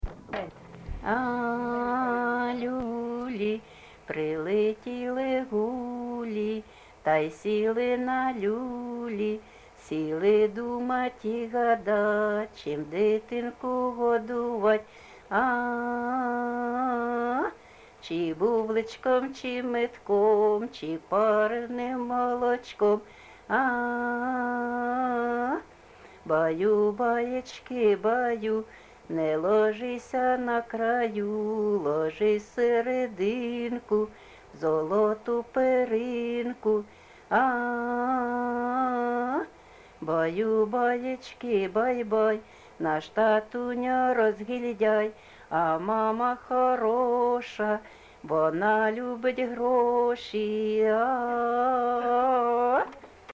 ЖанрКолискові
Місце записус-ще Ясна Поляна, Краматорський район, Донецька обл., Україна, Слобожанщина